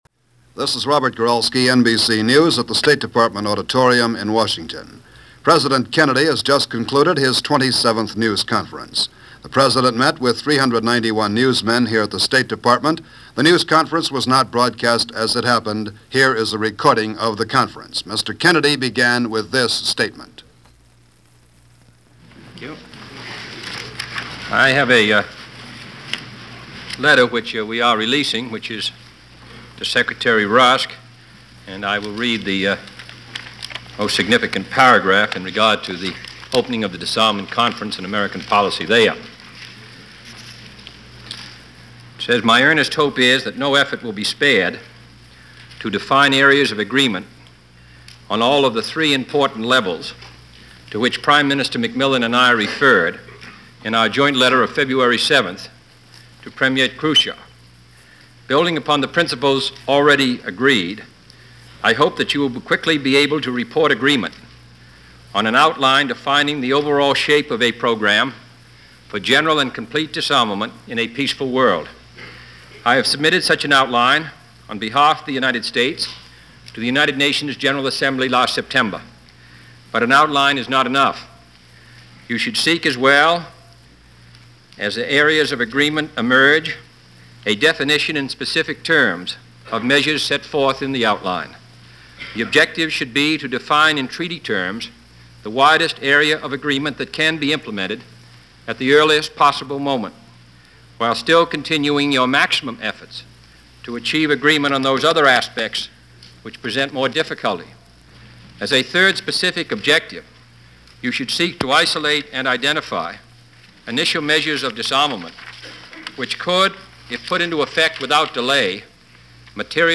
March 14, 1962 - A JFK Press Conference - From Taxes To Vietnam And A Brother Running For The Senate.
On this day in 1962, President Kennedy delivered his 27th News conference. He opened by reading a portion of a letter to Secretary of State Dean Rusk regarding the upcoming disarmament conference in Geneva and the goals the White House was hoping to accomplish during the talks.